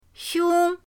xiong1.mp3